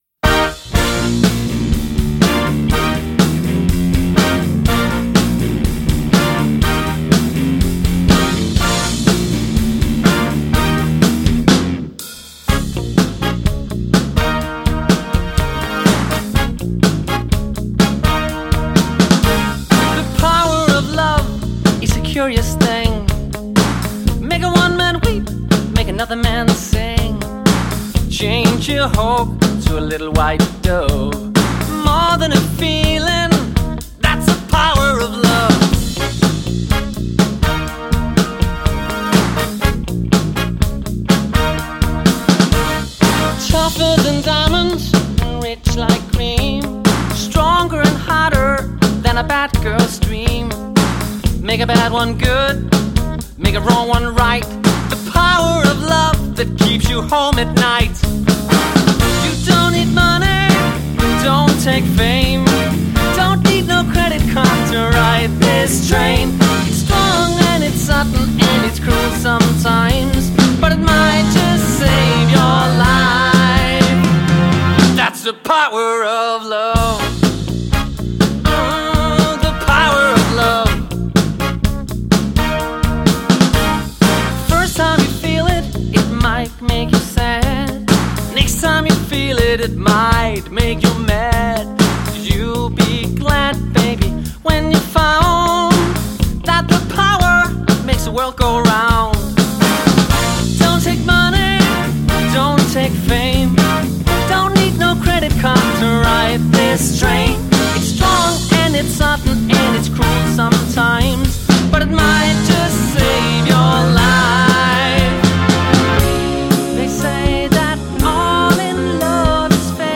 > 1 mandlig og 1 kvindelig forsanger
> 7-mands festband med masser af kor
• Allround Partyband